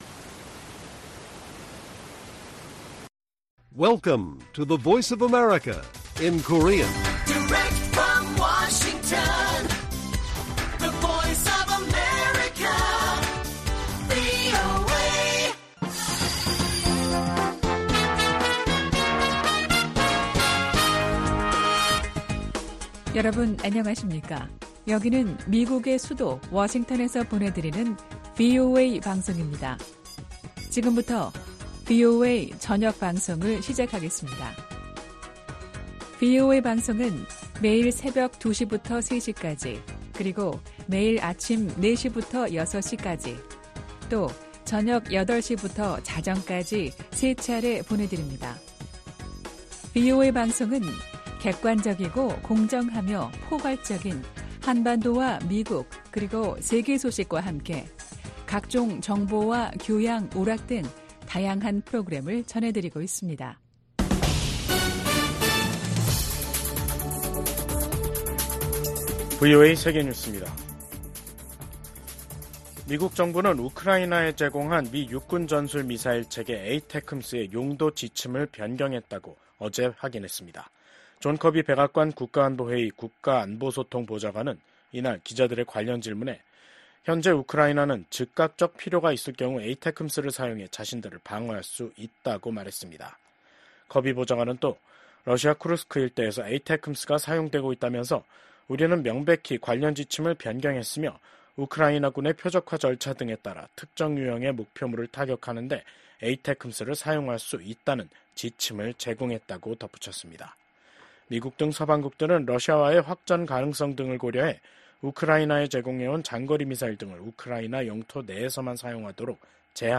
VOA 한국어 간판 뉴스 프로그램 '뉴스 투데이', 2024년 11월 26일 1부 방송입니다. 미국은 북한군이 현재 우크라이나로 진격하지는 않았다고 밝혔습니다. 러시아가 북한에 파병 대가로 이중용도 기술과 장비를 판매하고 있다고 미 국무부가 밝혔습니다. 북러 군사 밀착이 북한 군의 러시아 파병으로까지 이어지고 있는 가운데 북중 관계는 여전히 냉랭한 기운이 유지되고 있습니다.